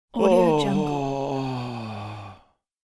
دانلود افکت صوتی فریاد بلند مرد
آهنگ صوتی رایگان Male Long Release Yell یک گزینه عالی برای هر پروژه ای است که به صداهای انسانی و جنبه های دیگر مانند sfx، صدا و صدا نیاز دارد.